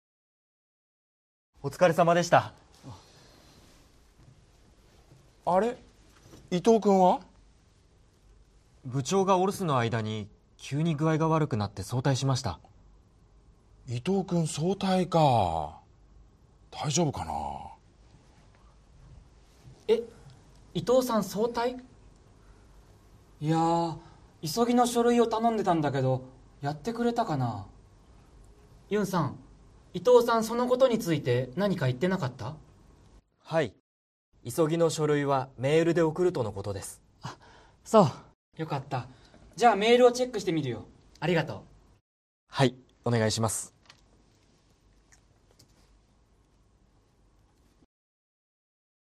Role-play Setup
skit05.mp3